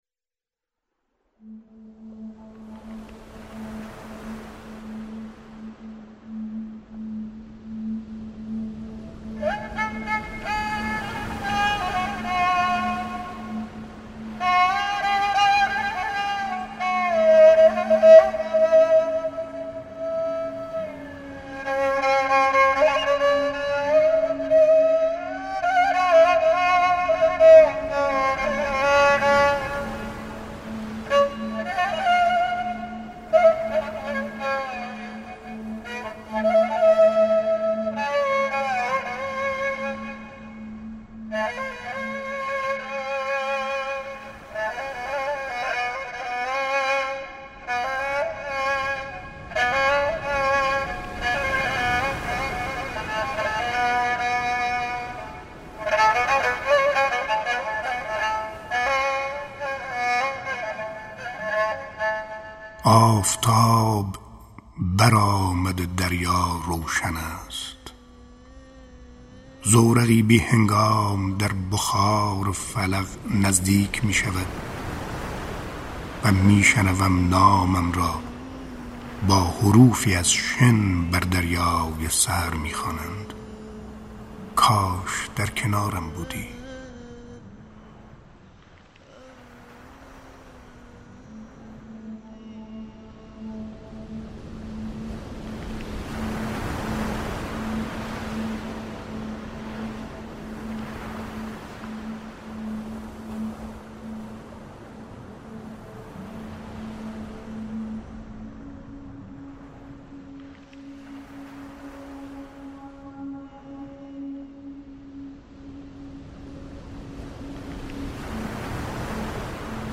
دانلود دکلمه آفتاب در آمده شمس لنگرودی با صدای شمس لنگرودی
گوینده :   [شمس لنگرودی]